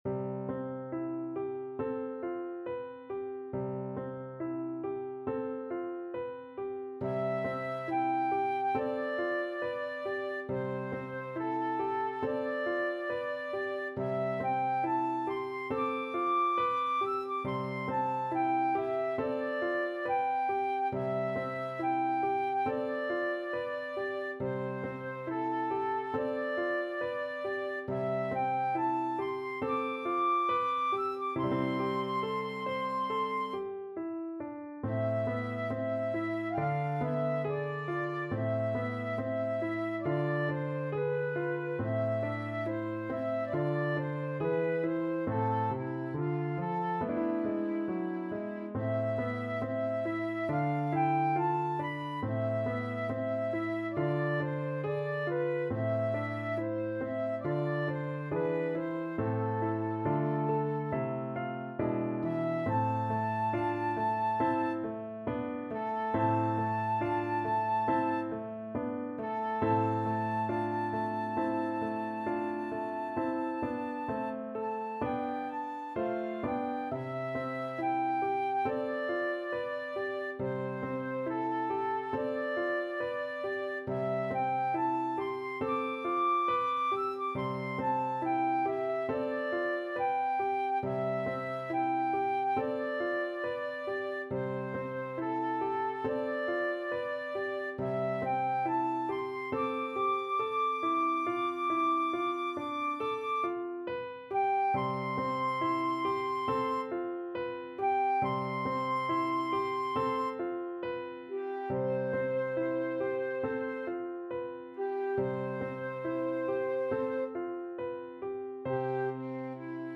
Flute
4/4 (View more 4/4 Music)
C major (Sounding Pitch) (View more C major Music for Flute )
~ =69 Poco andante
Classical (View more Classical Flute Music)